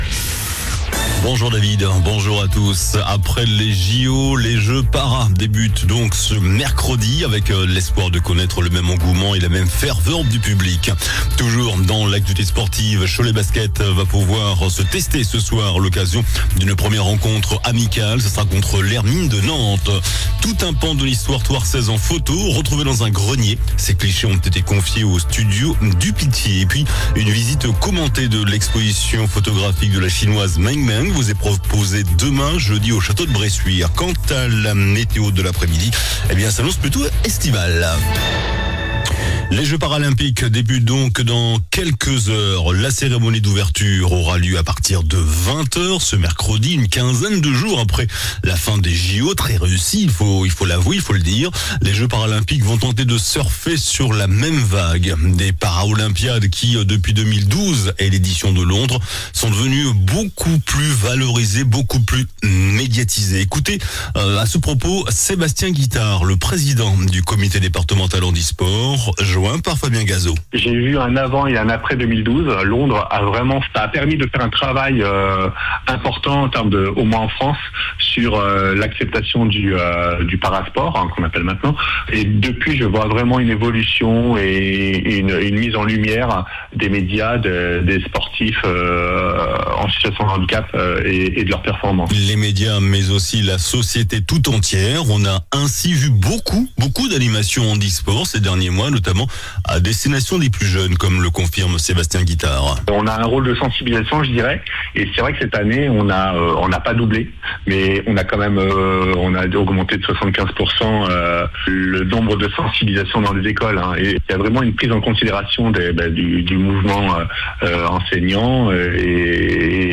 JOURNAL DU MERCREDI 28 AOÛT ( MIDI )